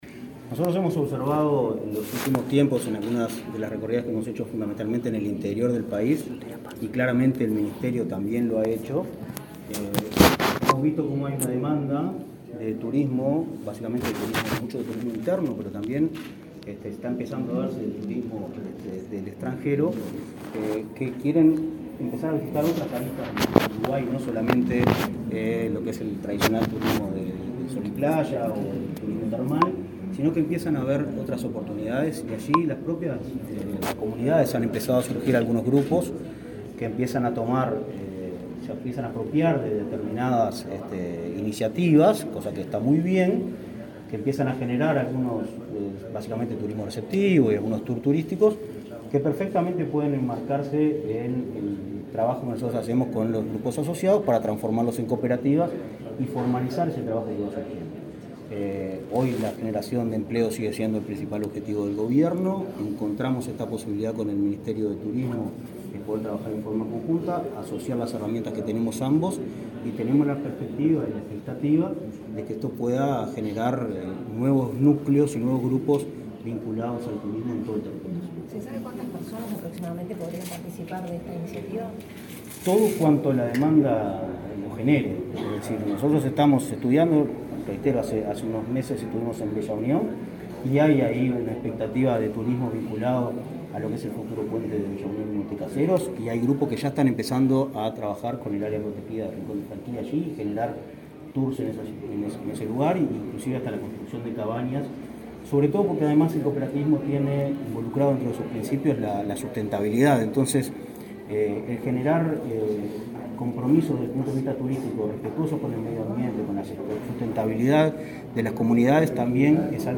Declaraciones a la prensa del presidente de Inacoop, Martín Fernández